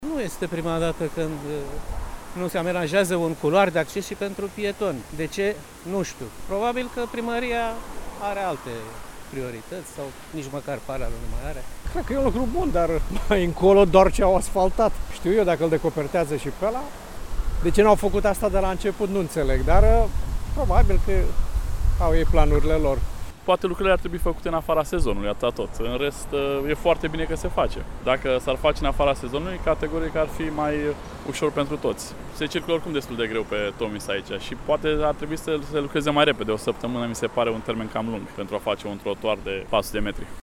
a consemnat opiniile pietonilor: